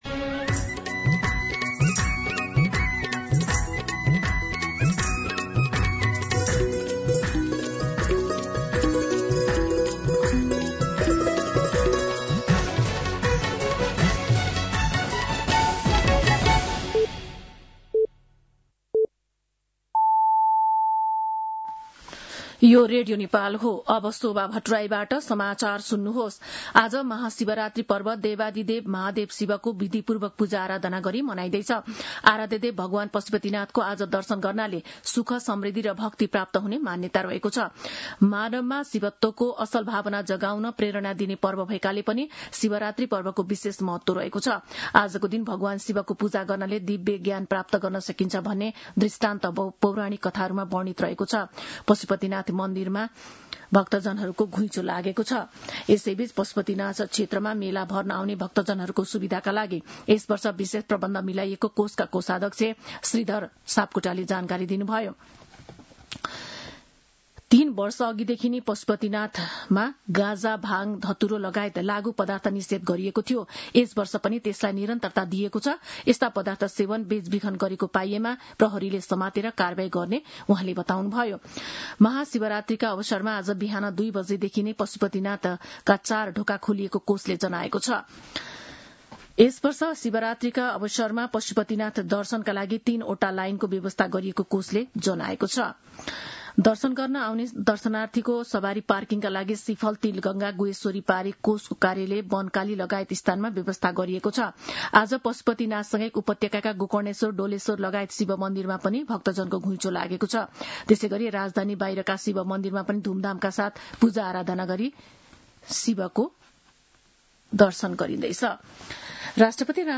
दिउँसो ४ बजेको नेपाली समाचार : ३ फागुन , २०८२
4pm-News-11-03.mp3